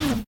whiz_00.ogg